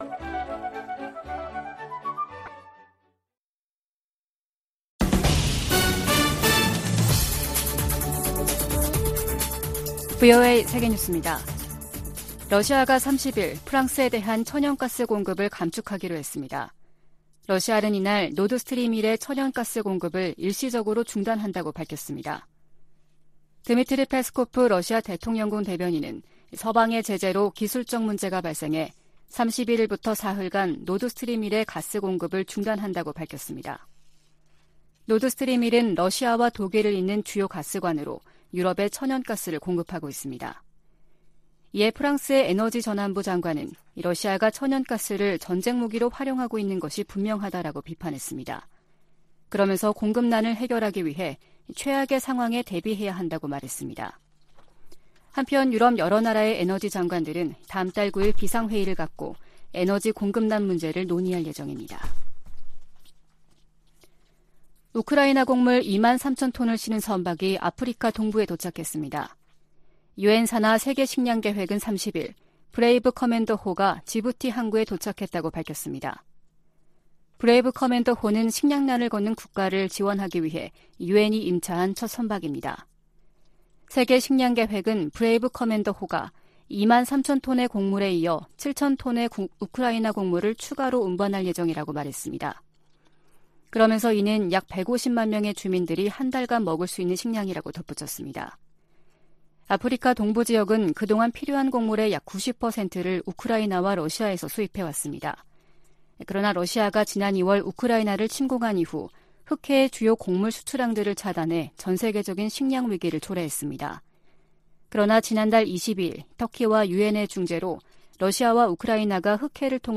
VOA 한국어 아침 뉴스 프로그램 '워싱턴 뉴스 광장' 2022년 8월 31일 방송입니다. 미 국무부는 북한과의 대화 필요성을 재확인하면서도 도발에 대응하고 제재를 이행하겠다는 의지를 강조했습니다. 권영세 한국 통일부 장관은 ‘담대한 구상’ 제안에 북한이 호응할 것을 거듭 촉구했습니다. 미 의회 내에서는 북한이 핵실험을 강행할 경우 북한과 거래하는 중국 은행에 ‘세컨더리 제재’를 가해야 한다는 목소리가 높습니다.